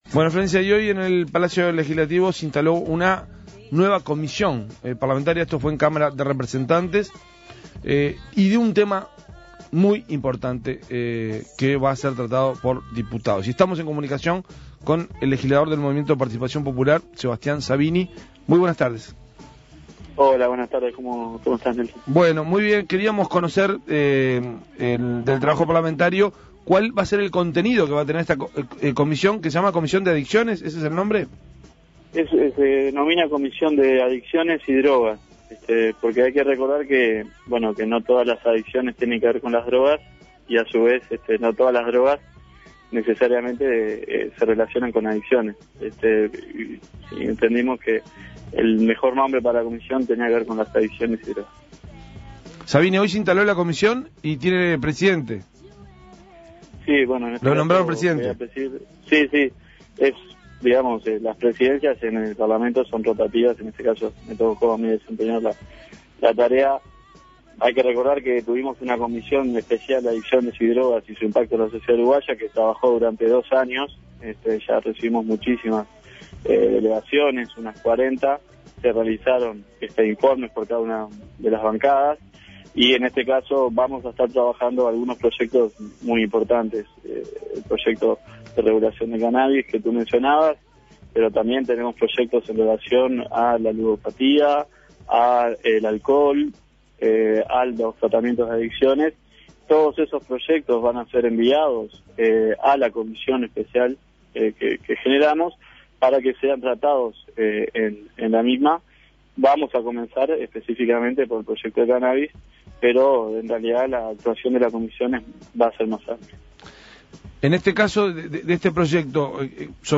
Escuche la entrevista al diputado del MPP, Sebastián Sabini